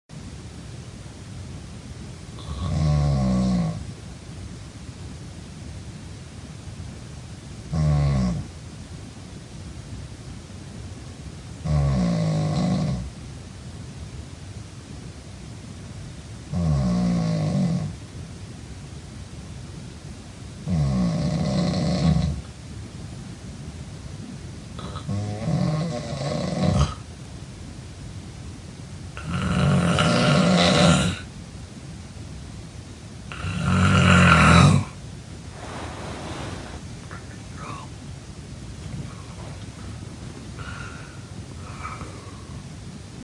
Snoring 2 Sound Button - Free Download & Play